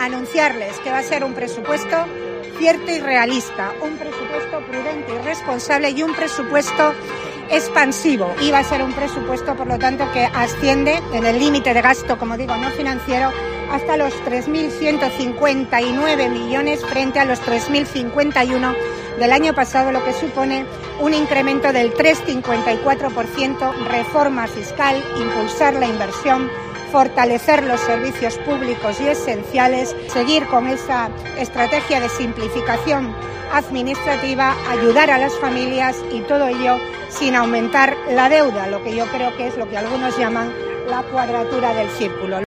En su intervención ante los medios de comunicación presentes en el entorno del Santuario de Hoz de Marrón, Buruaga ha pedido a la Virgen Bien Aparecida que “cuide de su pueblo”, así como “ayuda y acierto” para hacer frente a las dificultades, tomar “las decisiones correctas” y ofrecer, así, a la ciudadanía “la respuesta y soluciones que esperan” de este Gobierno y su presidenta.